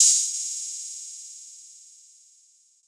Open Hats